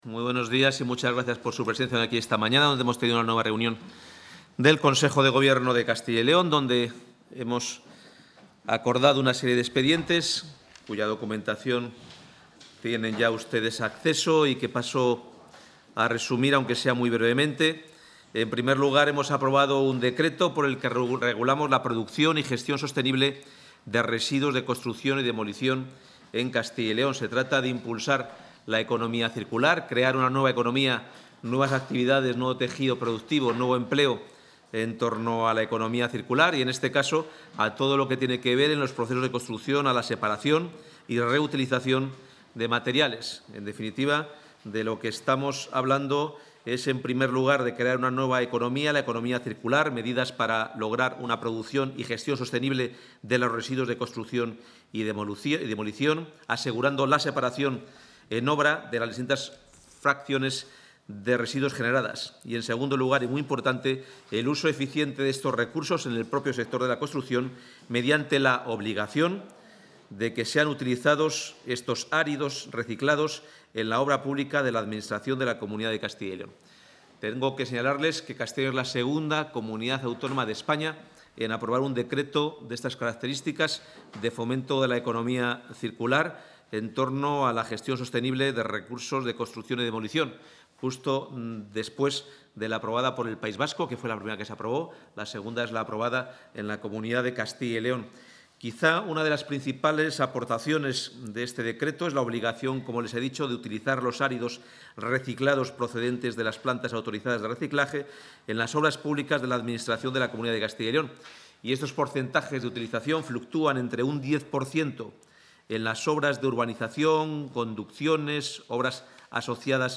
Intervención del portavoz de la Junta.